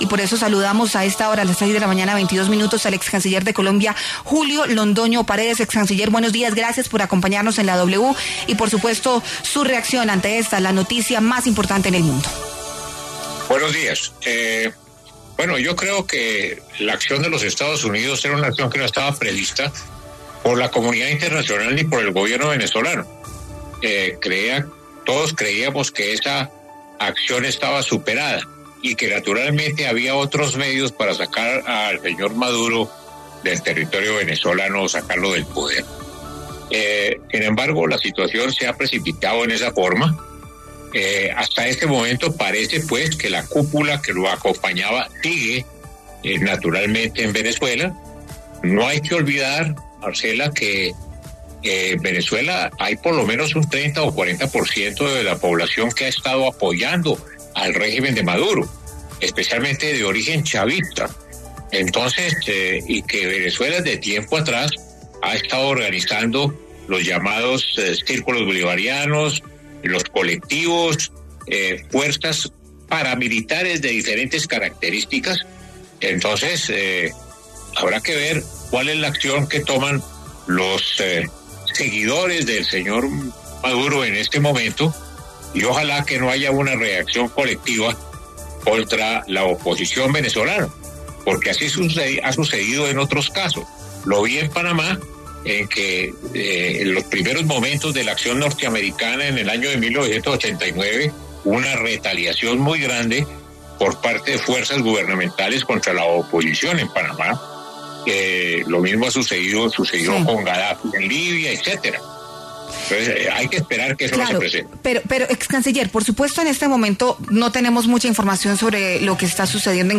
El excanciller de Colombia Julio Londoño Paredes pasó por los micrófonos de La W para hablar de la captura del líder del chavismo, Nicolás Maduro, por parte de Estados Unidos, como lo confirmó Donald Trump.